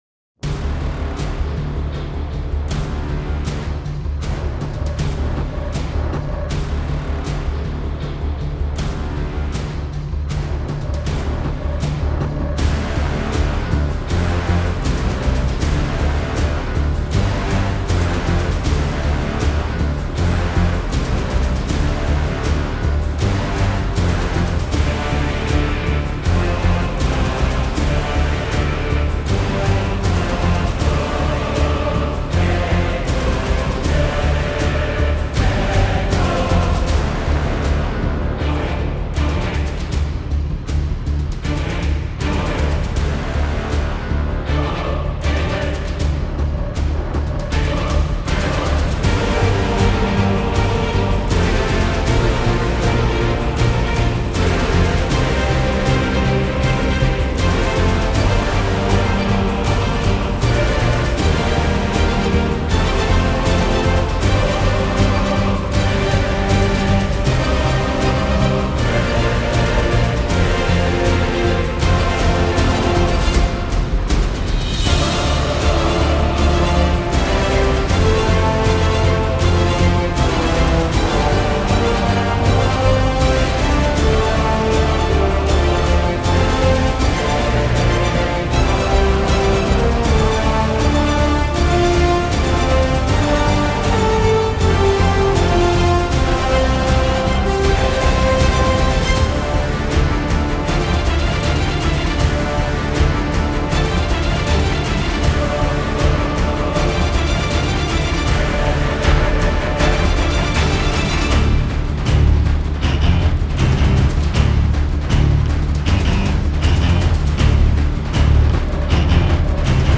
Genre: filmscore, trailer.